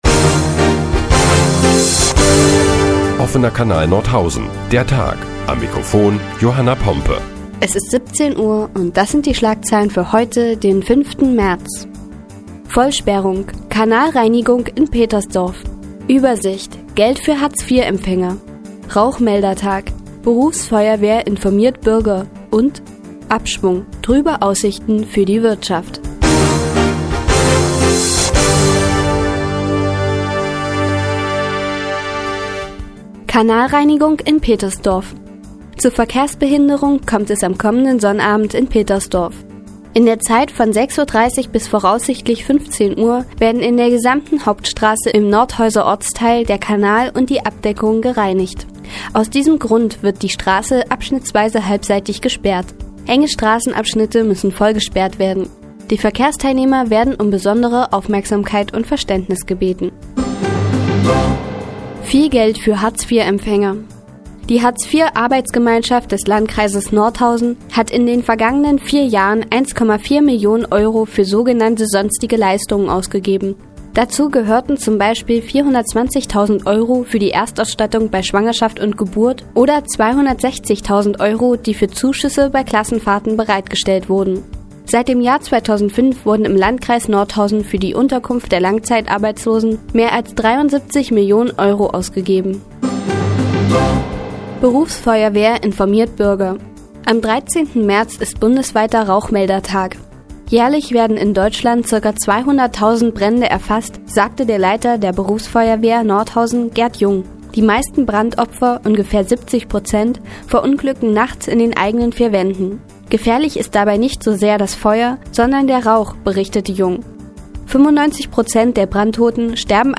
Die tägliche Nachrichtensendung des OKN ist nun auch in der nnz zu hören. Heute geht es unter anderem um Geld für Hartz-IV-Empfänger und um den bundesweiten Rauchmeldertag.